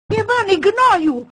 Worms speechbanks
firstblood.wav